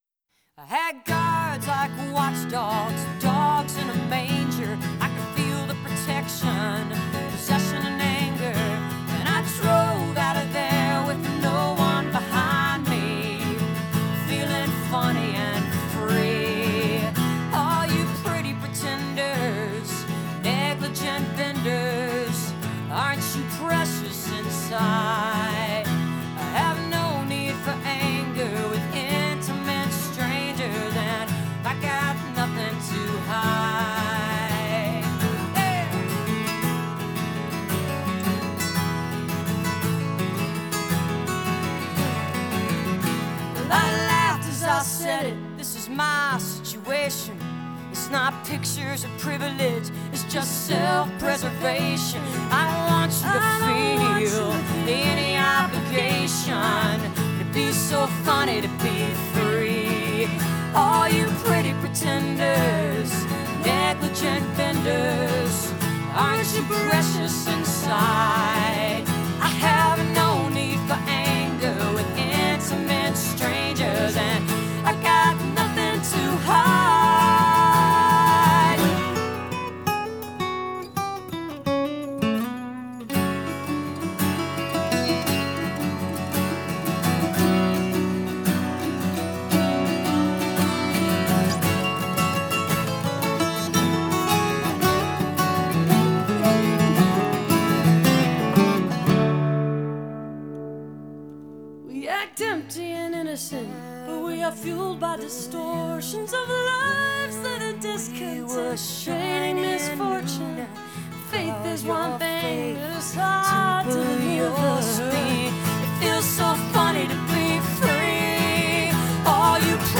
acoustic mix